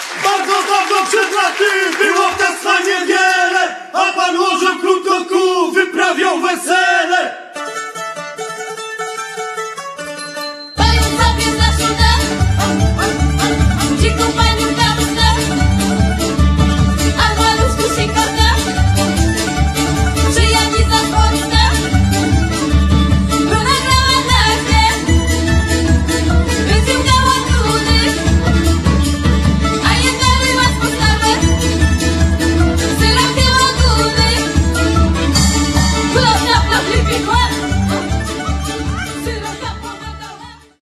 Zaprezentowali niezwykłe bogactwo brzmienia.
kontrabas